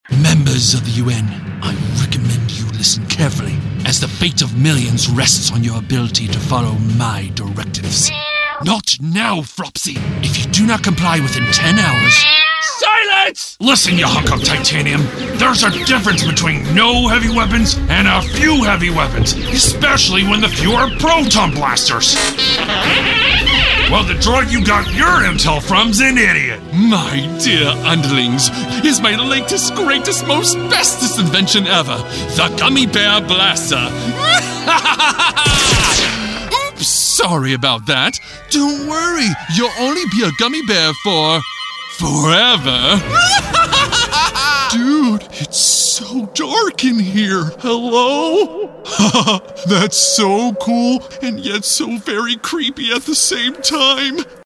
Voice overs produced by US and international actors.